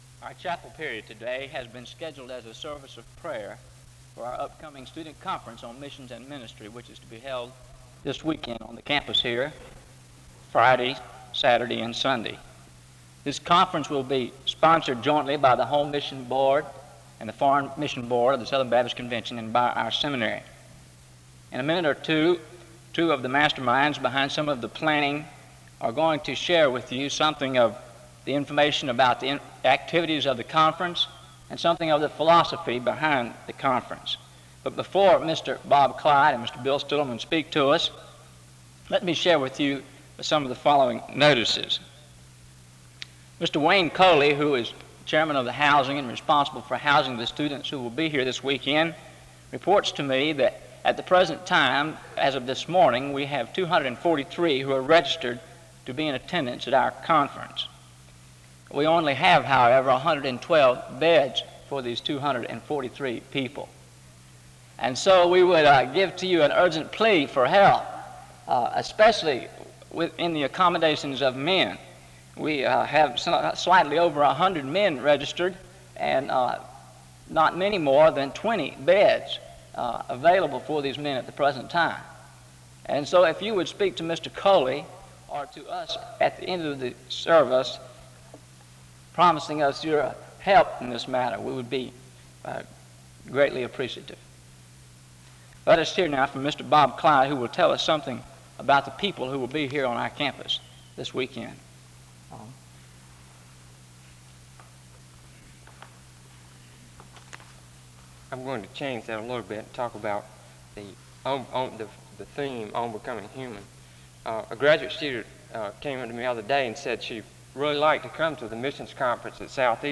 SEBTS Chapel - Student Prayer Service February 4, 1969
SEBTS Chapel and Special Event Recordings